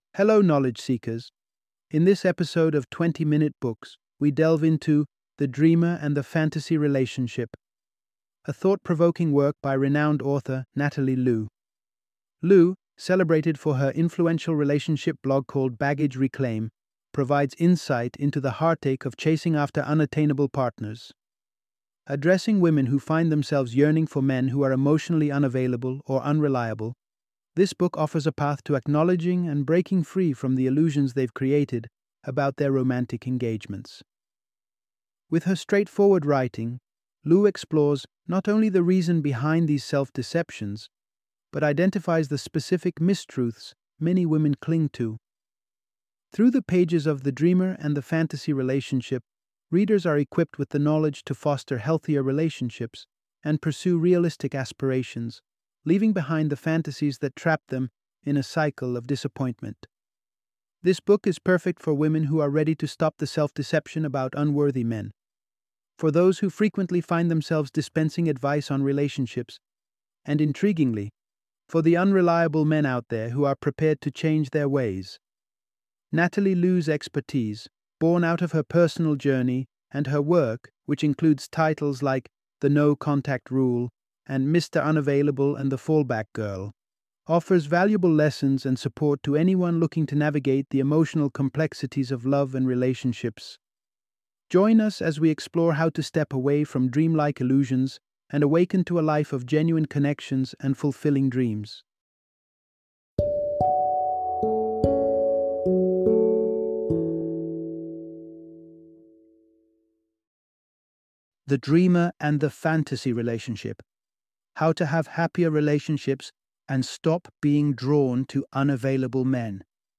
The Dreamer and the Fantasy Relationship - Audiobook Summary